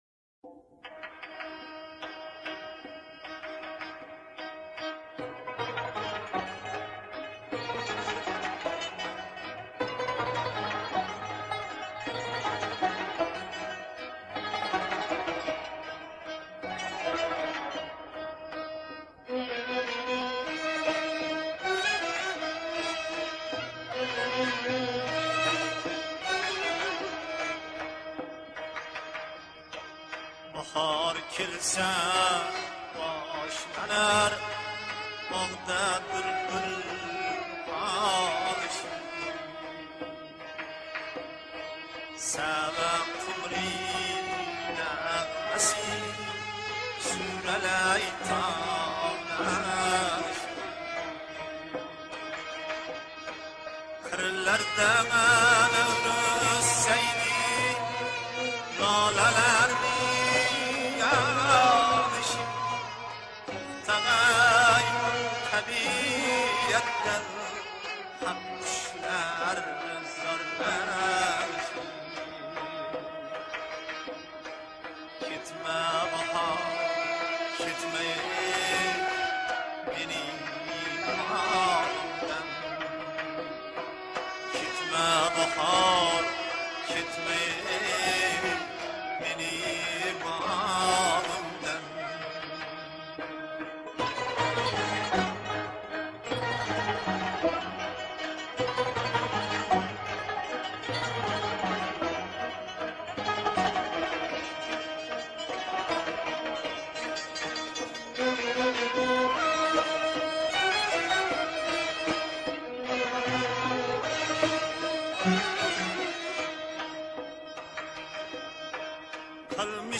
Ўзбекистон мусиқаси